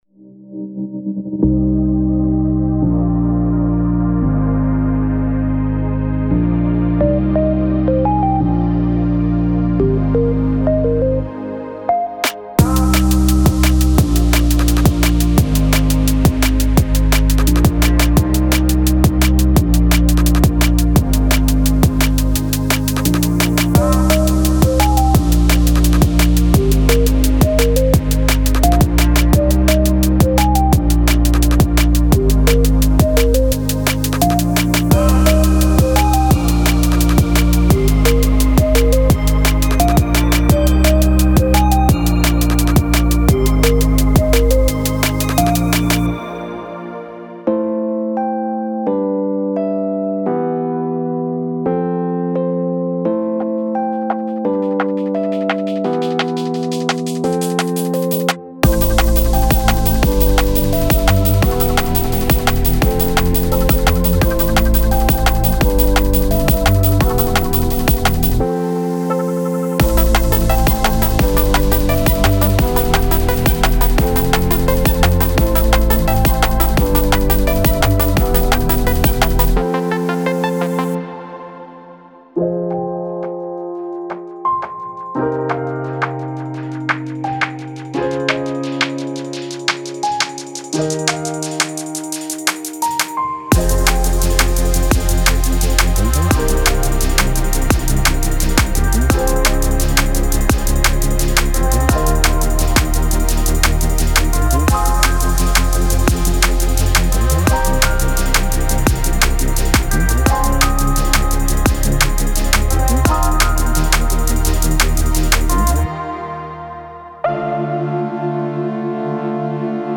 Genre:Liquid
テンポは172 BPMに固定されており、制作の出発点となるような素材が多数収録されています。
映画のような雰囲気と感情のエネルギーが、あなたのトラックの中で息づきます。
デモサウンドはコチラ↓